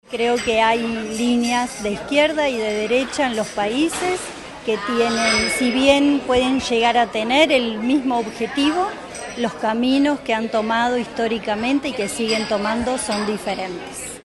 Tras su exposición, la viceministra del Interior de Uruguay, Gabriela Valverde, fue consultada sobre el proceso salvadoreño. La autoridad evitó pronunciarse sobre políticas internas de otros países y enfatizó que Uruguay respeta su republicanismo y su democracia; por lo que, dijo, no corresponde emitir juicios sobre decisiones soberanas.